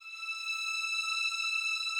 strings_076.wav